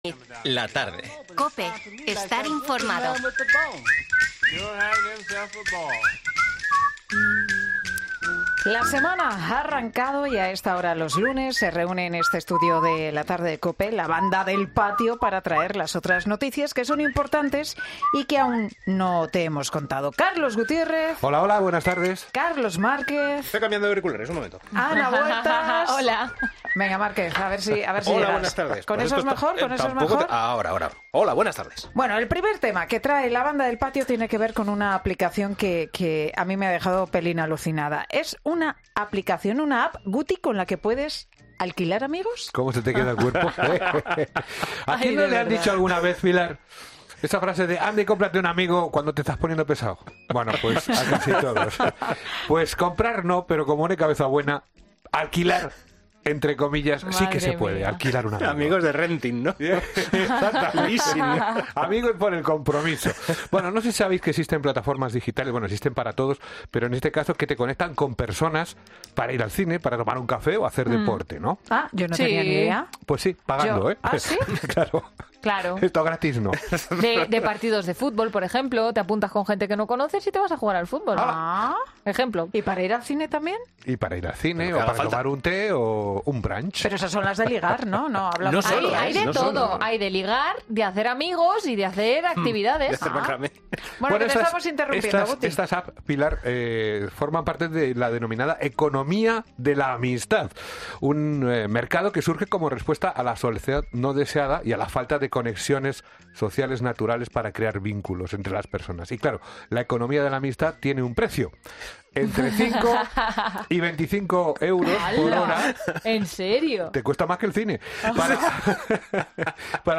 A pesar de sus ventajas, el concepto ha generado un debate en el estudio de COPE, donde los colaboradores han expresado ciertos "escalofríos" ante la idea de tener que pagar por compañía.